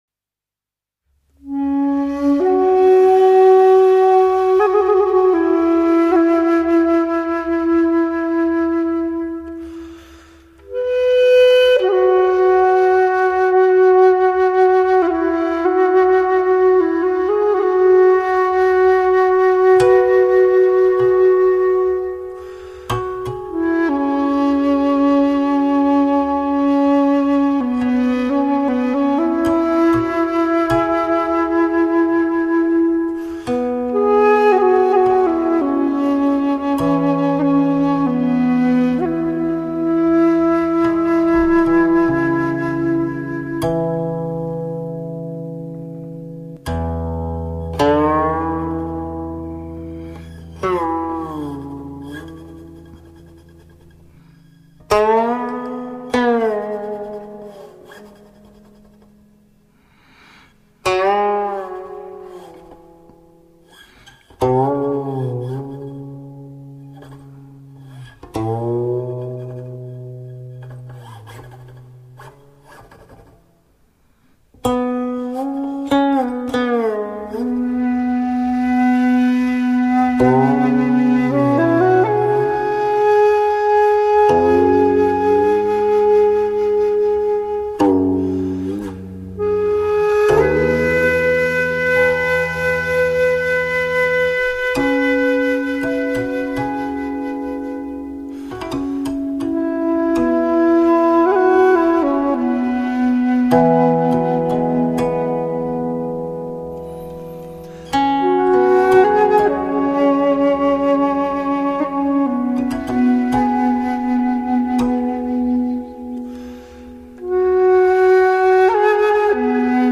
箫
琵琶
录音棚：Village Studios
心定气闲、空谷幽香，原创琴曲，值得聆听。